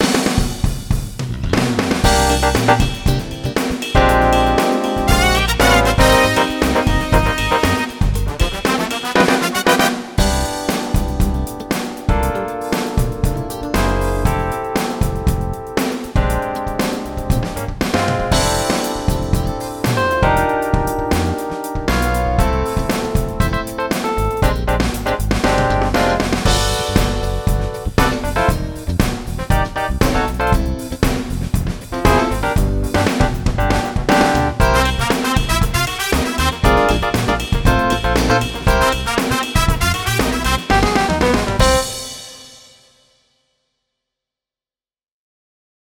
Funky /újra/ az SD9 SF2-vel egy kis Vintage Drum-mal fűszerezve!
Funky.mp3